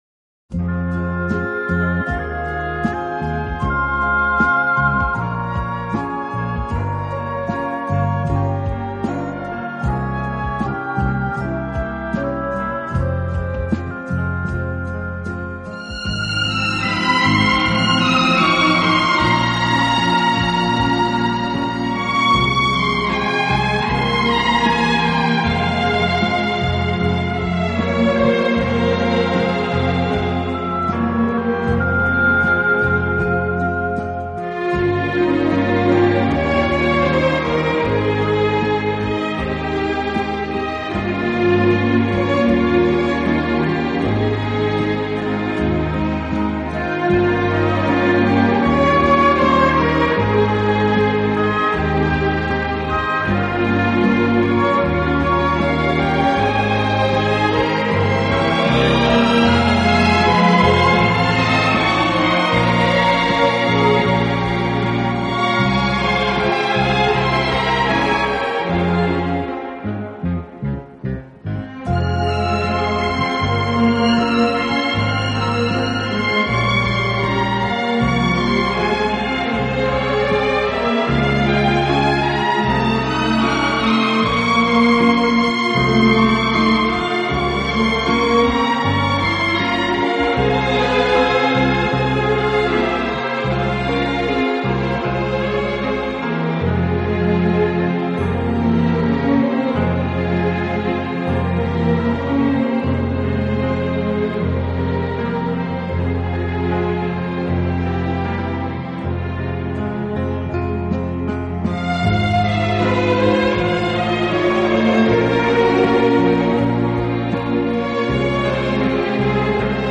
【轻音乐】
美感，音色圆润，弦乐突出。